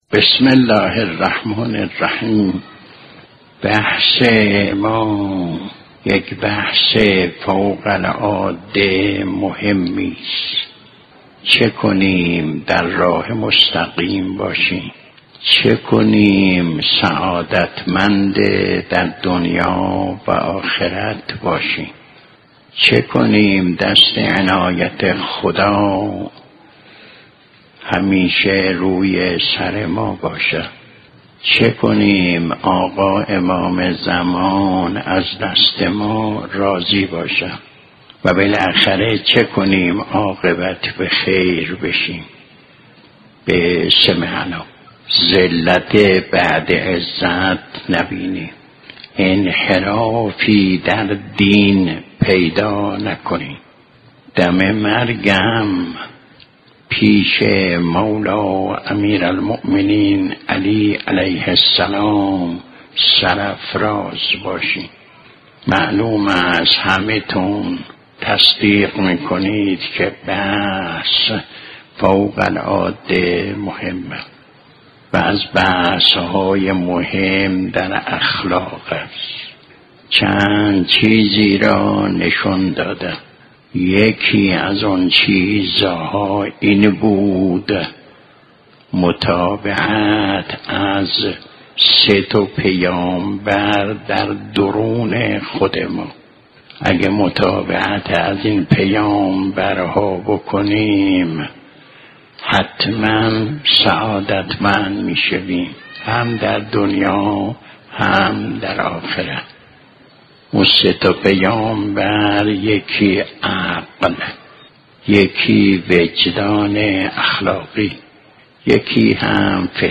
سرای سخن برنامه است كه سخنرانی بزرگان را ساعت 3:30 از رادیو ایران پخش می كند.